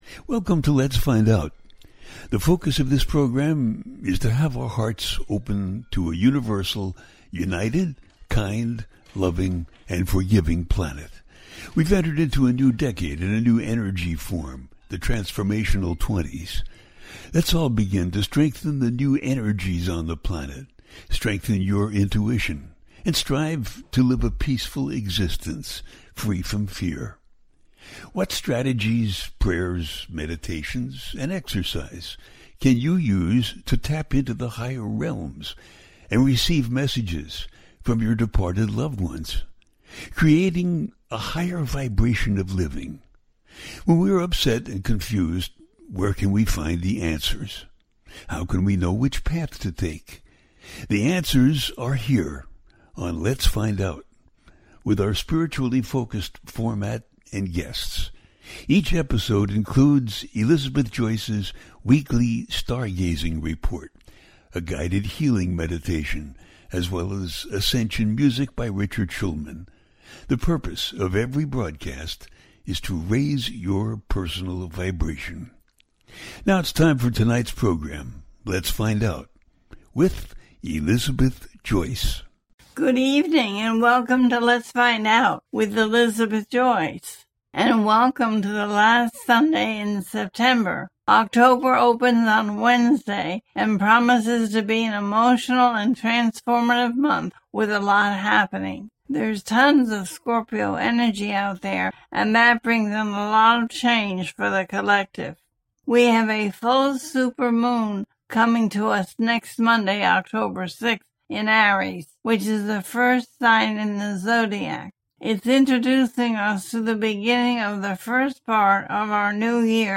Full Super Moon In Aries, Happy New Era - A teaching show